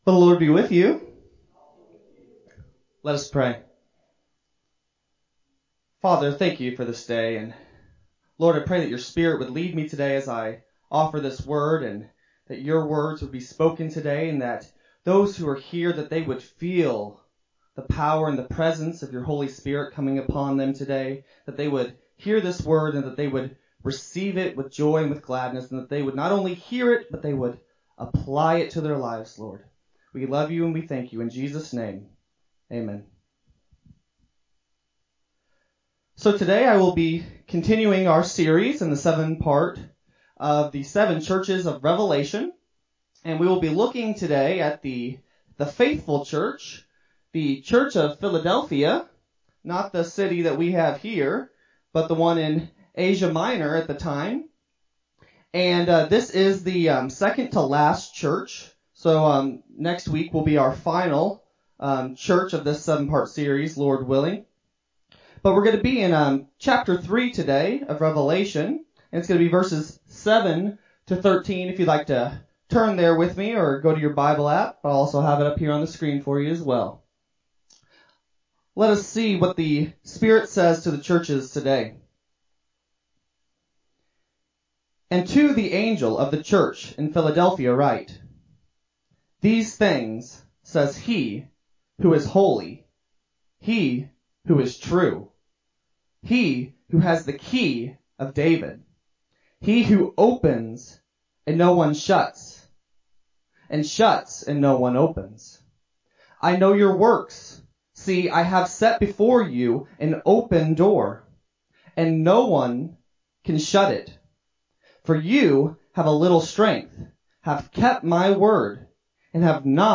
7-12-20-Sermon-CD.mp3